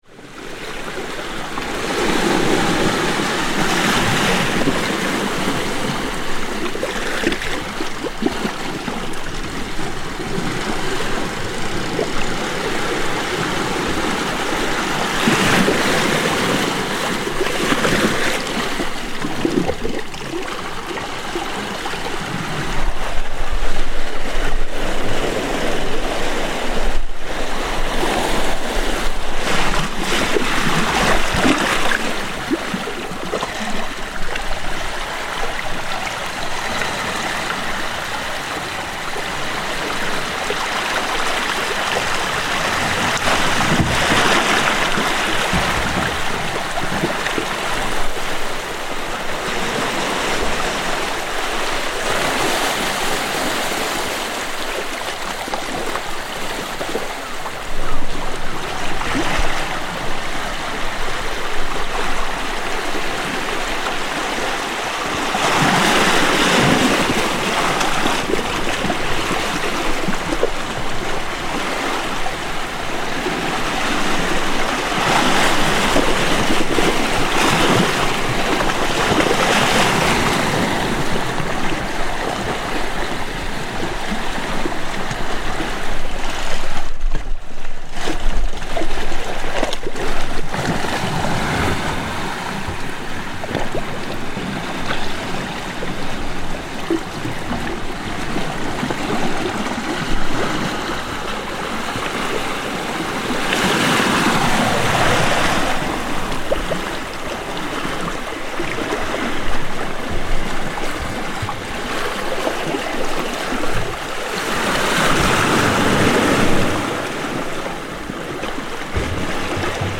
Waves on the jetty
Light waves breaking on a stone boat jetty in the very small one-boat Boscastle harbour in Cornwall on a sunny August afternoon.